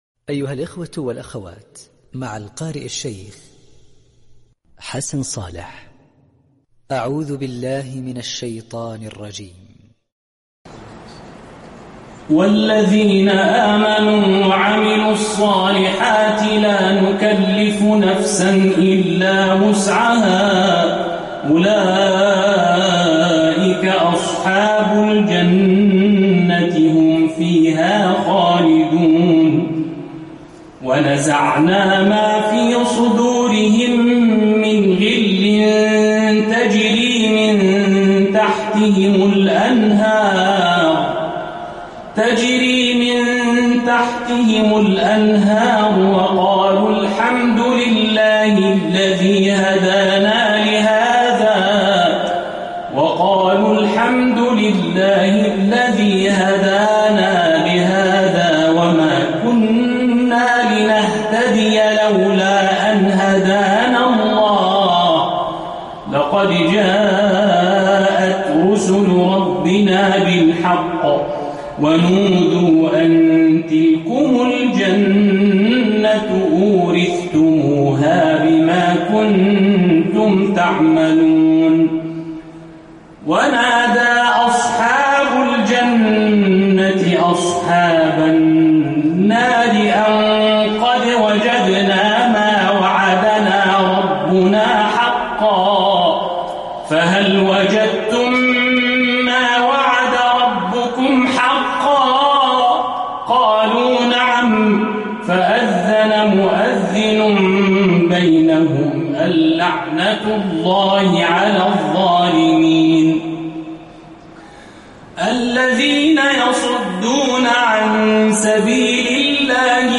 Surat Al Aaraaf Reciter